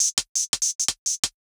UHH_ElectroHatA_170-02.wav